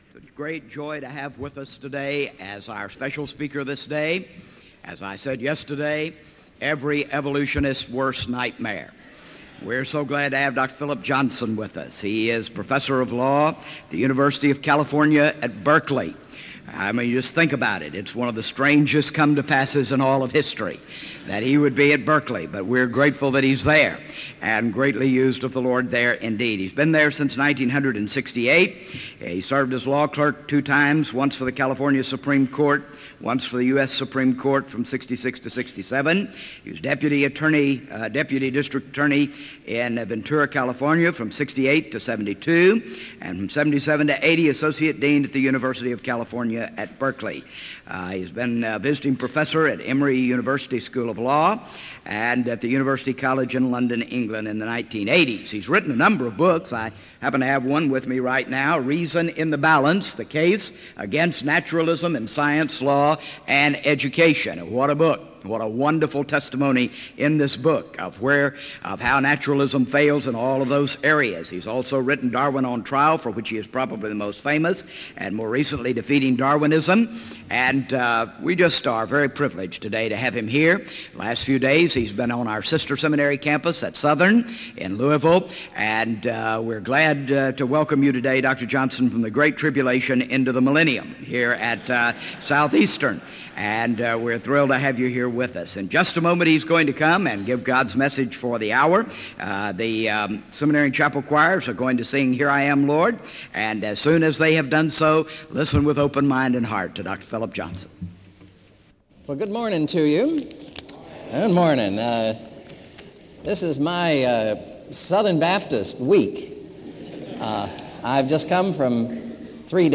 SEBTS Chapel - Phillip Johnson March 25, 1998
In Collection: SEBTS Chapel and Special Event Recordings SEBTS Chapel and Special Event Recordings - 1990s Thumbnail Titolo Data caricata Visibilità Azioni SEBTS_Chapel_Phillip_Johnson_1998-03-25.wav 2026-02-12 Scaricare